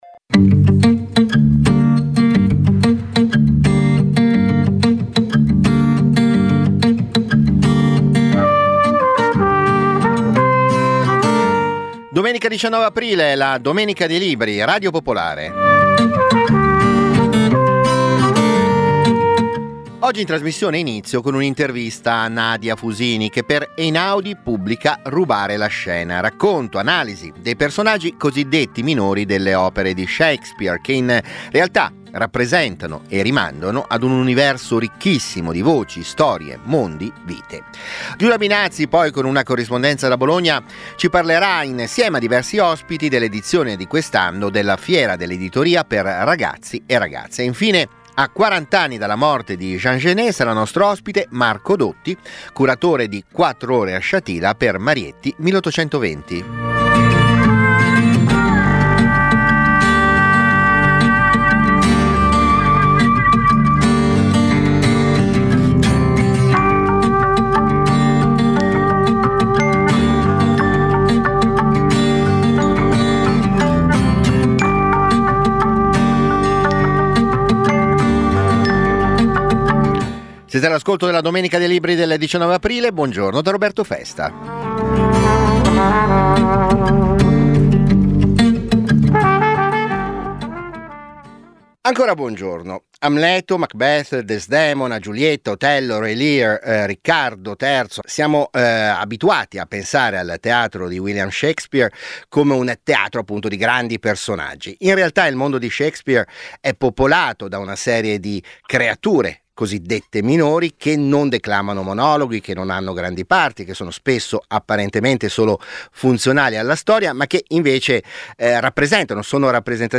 Ogni domenica, dalle 10.35 alle 11.30, interviste agli autori, approfondimenti, le novità del dibattito culturale, soprattutto la passione della lettura e delle idee.